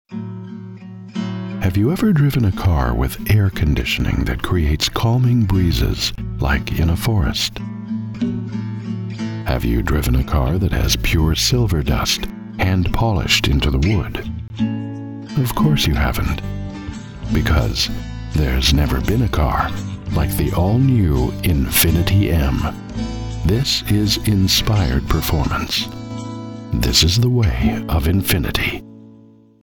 Smooth National TV On Spec Voice Over